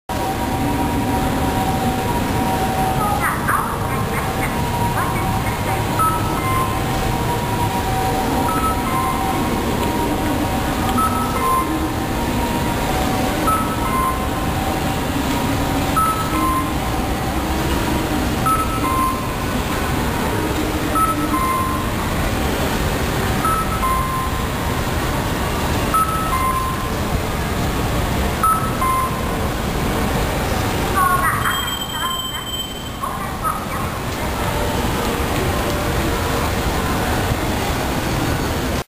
これは交差点名も言っているみたいですが、何とと言っているか全く分からなかったのでパスしています。
なおかつカッコーの音程がこのタイプは全て高いですね。
けど、どうやら鳴き交わしでは無いようですね？